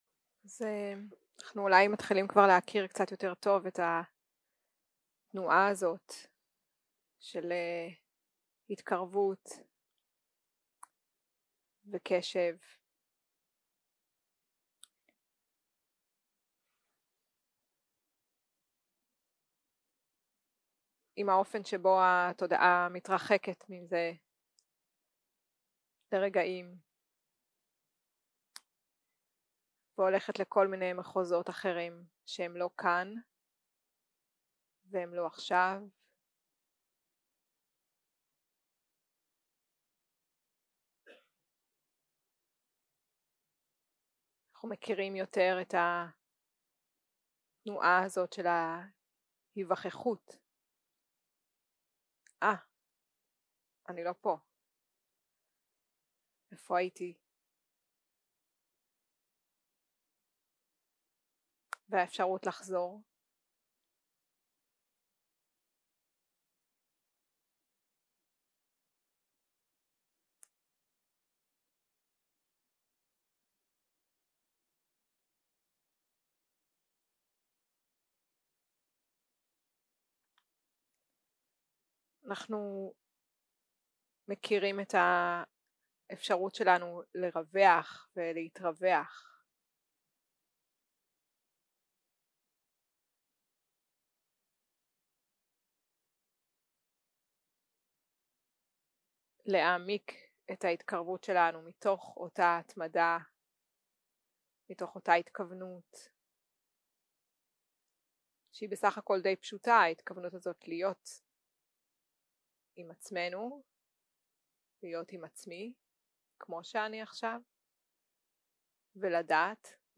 יום 3 - הקלטה 6 - צהרים - מדיטציה מונחית - תשומת לב אוהדת
סוג ההקלטה: מדיטציה מונחית